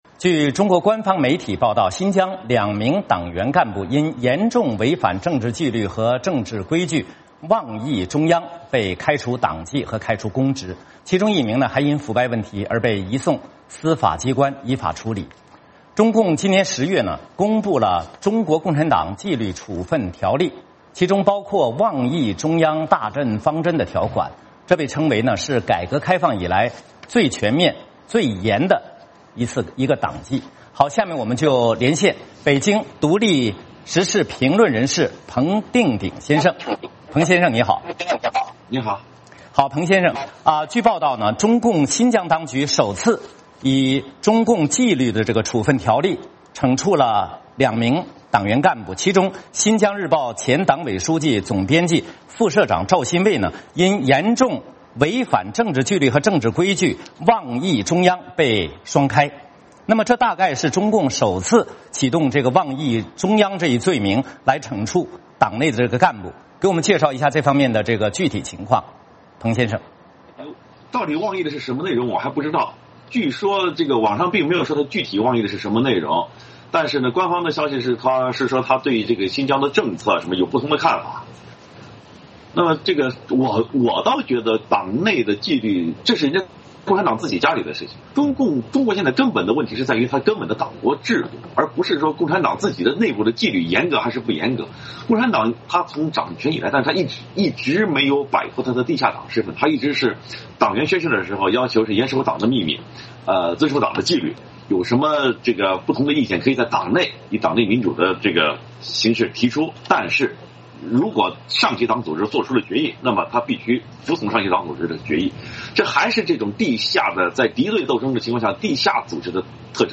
VOA连线：“妄议中央”：新疆两名党员干部被双开